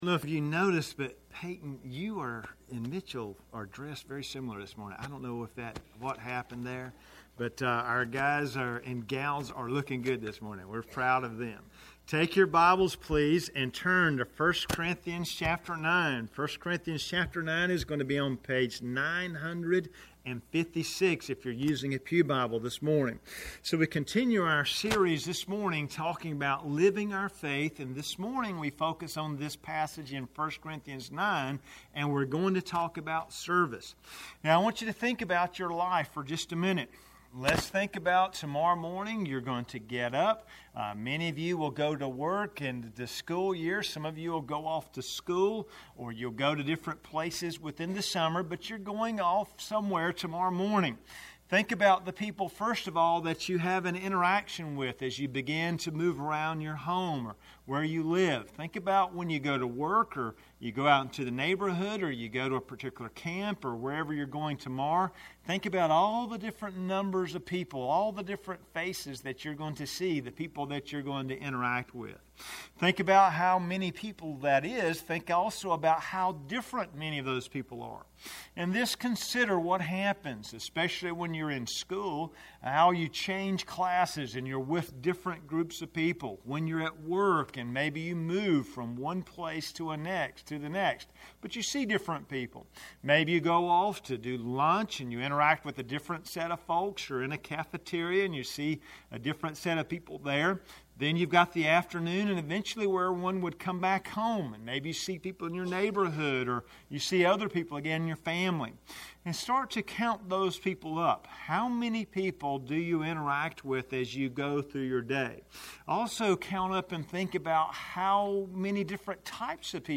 Sermons - Tippett's Chapel Free Will Baptist Church
Sermon library of Tippett’s Chapel Free Will Baptist Church in Clayton, NC enables listeners to easily browse our Sunday morning sermons and worship services.